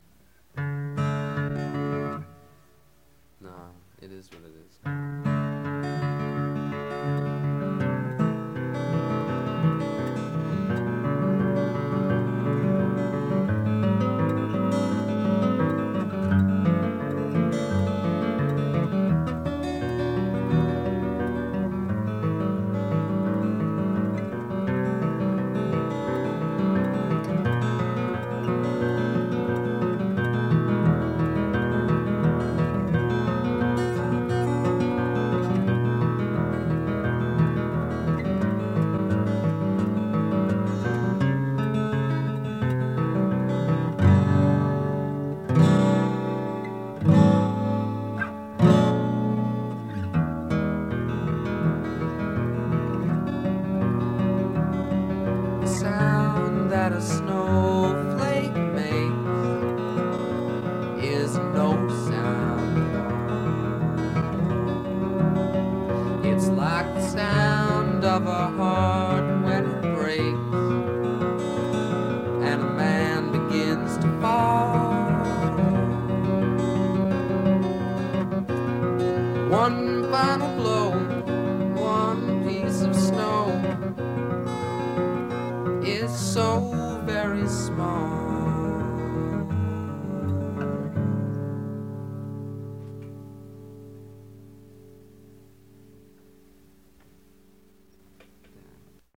Recorded at Antioch College February 24, 1972.